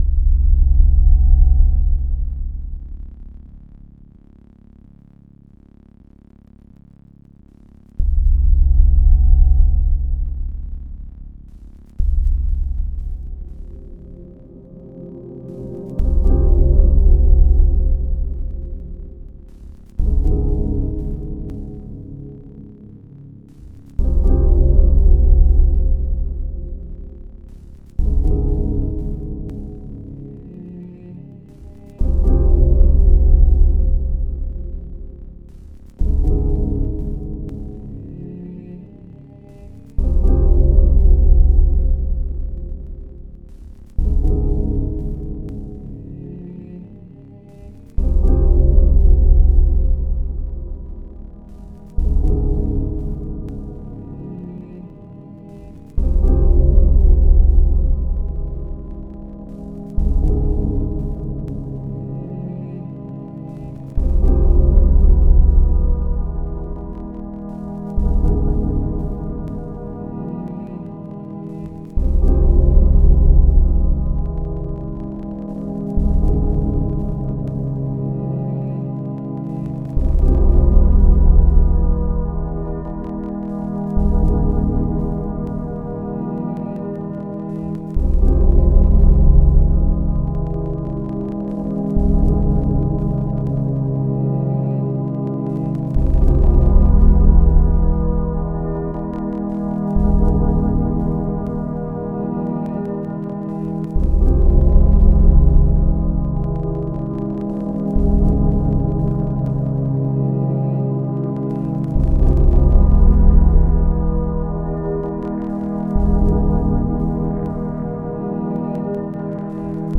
only the second track ever completed in ableton live. tracks recorded out and assembled in adobe audition. so i'm not sure what the sound mix is gonna be like on this. i'm working on these really bassy headphones and haven't heard it on anything else yet. its a really simple piece, as this is all i can manage in my current setup. doesn't have a name yet.
i should mention there are no breakbeats in this track and its ambient so sit back turn off the screen and close your eyes. if that doesnt sound good then don't listen
Love the string sounds, and how the different melodic elements are slightly detuned from one another --has a very ominous effect. This track gave me a sense of foreboding in a good way.
after about the fourth listen, my favorite thing about this is how the bit of static, the vibrato on the strings, the aforementioned dissonance all provide an intriguing delicateness to this track which surprisingly isn't disrupted by the jackhammer that comes in at the end. i think that is a really cool and interesting effect, whether you intended it or not. in fact, i just let out a little bit of pee thinking about it.